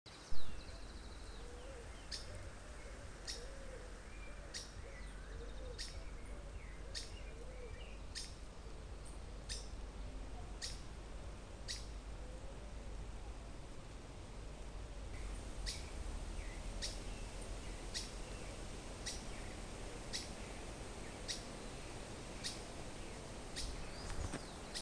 Scientific name: Myiodynastes maculatus solitarius
English Name: Streaked Flycatcher
Life Stage: Adult
Location or protected area: Punta Indio
Condition: Wild